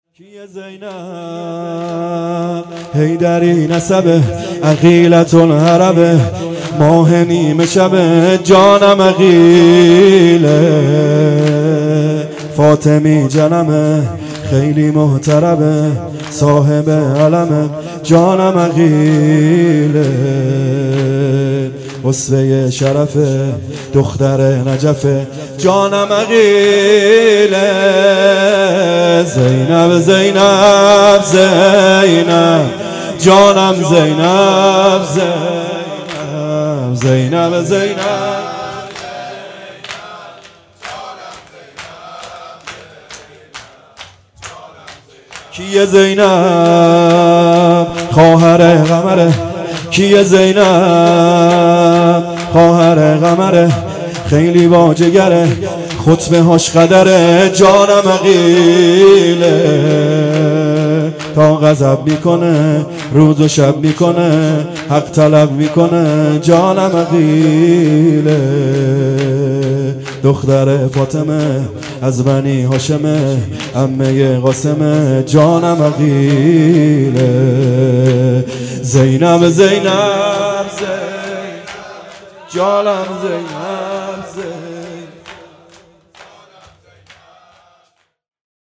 شب چهارم محرم الحرام 1441